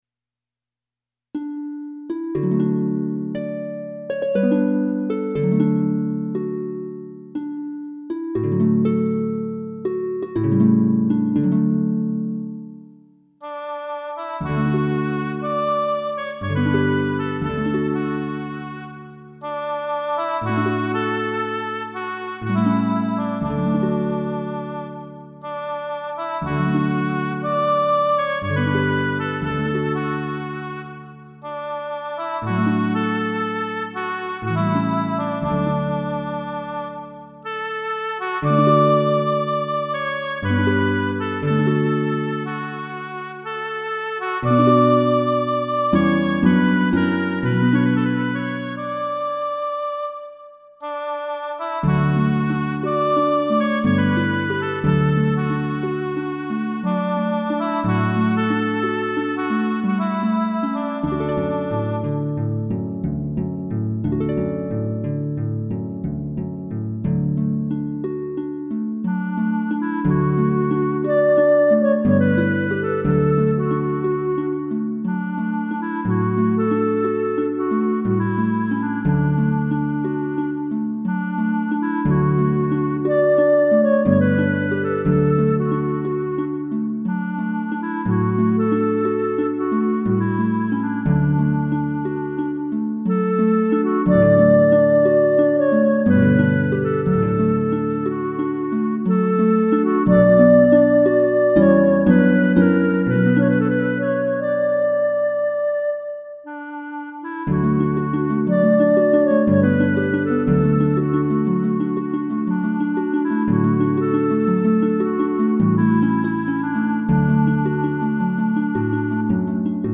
曲名の前のをクリックするとその曲のメロディーが聞けます。